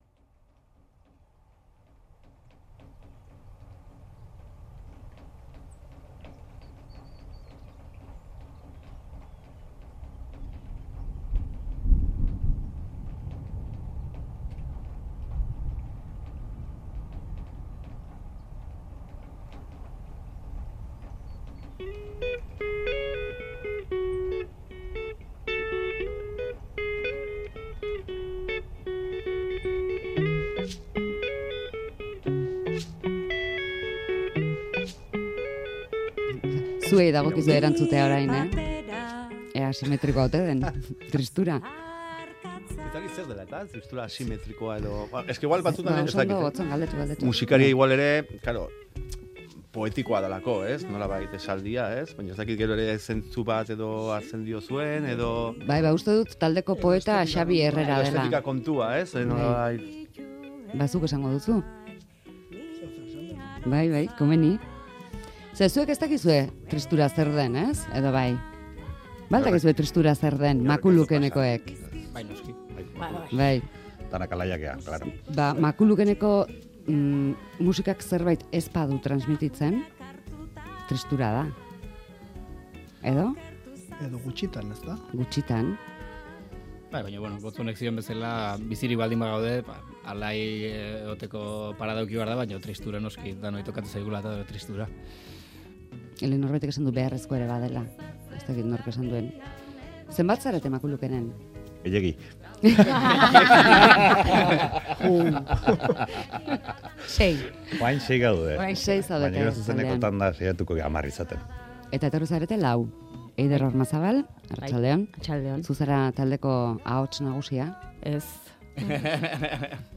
Audioa: Lau taldekidek egin digute bisita kantu berriez kontatzeko.